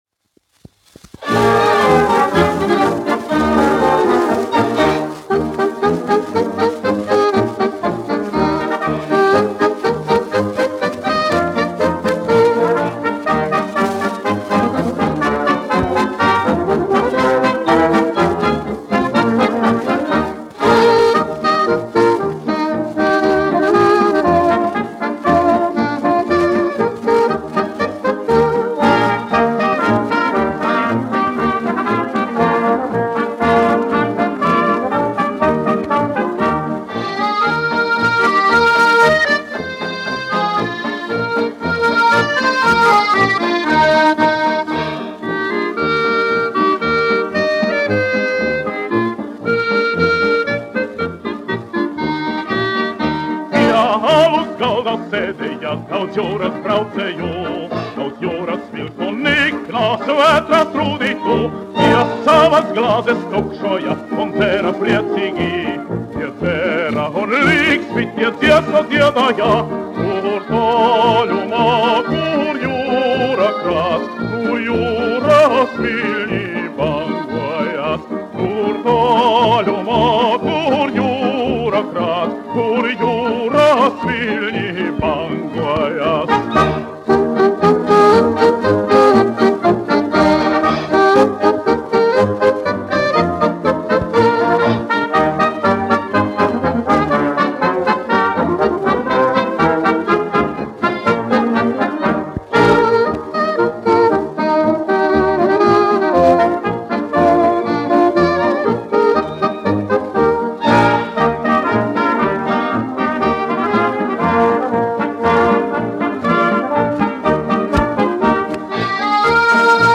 1 skpl. : analogs, 78 apgr/min, mono ; 25 cm
Fokstroti
Populārā mūzika -- Latvija
Skaņuplate